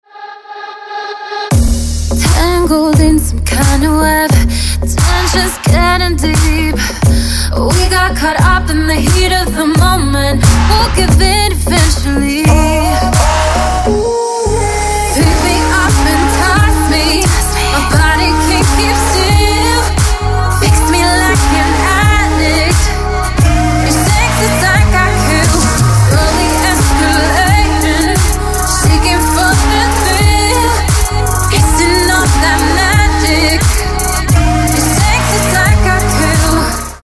• Качество: 320, Stereo
поп
громкие
мощные
dance
RnB
красивый женский голос
сексуальный голос